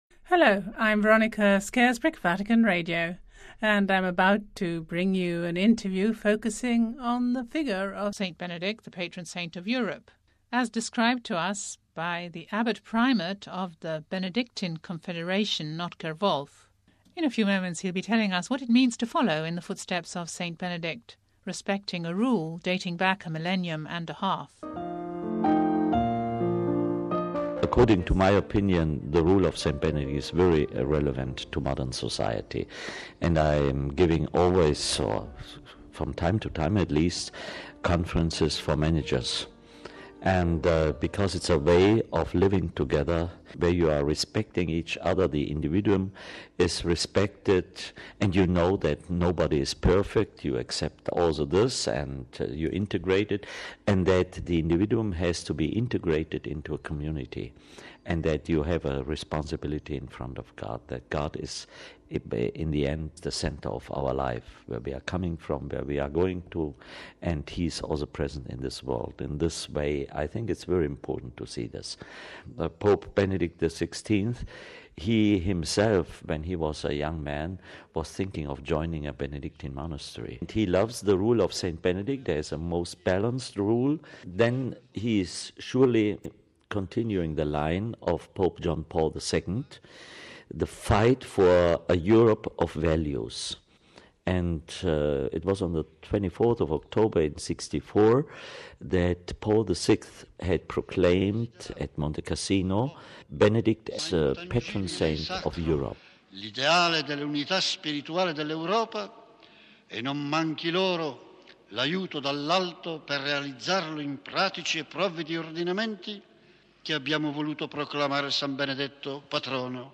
In this programme you can also hear a Vatican Radio archive recording of Pope Paul VI as he proclaims Benedict Patron Saint of Europe in October 1964 ...